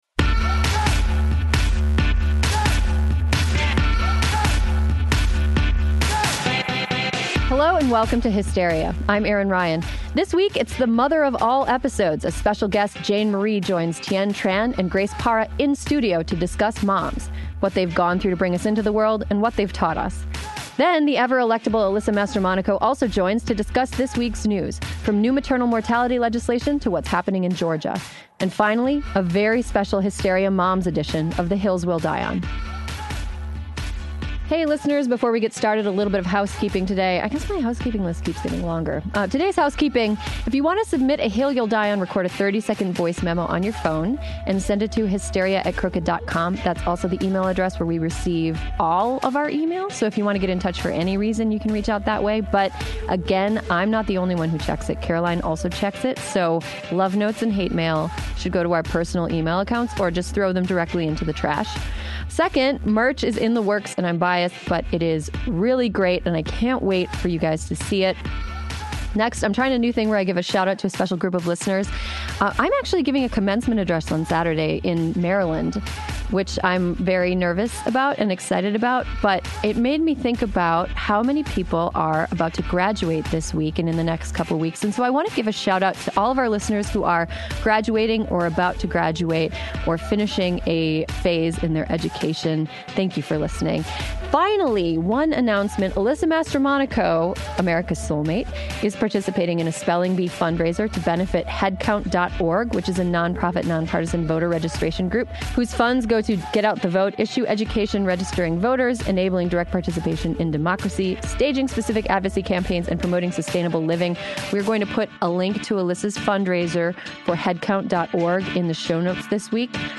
join in-studio to discuss moms